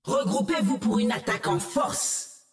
友方释放语音